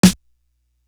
Real Mobb Do Snare.wav